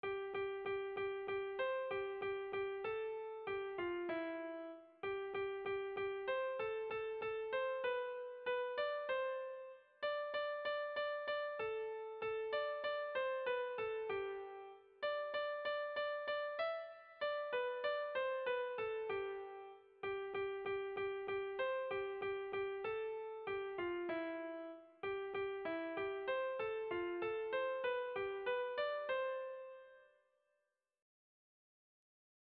Kontakizunezkoa
Errepikapenak ditu 8ko txikiko bertso doinu honek.
Zortziko txikia (hg) / Lau puntuko txikia (ip)
ABD1D2AA2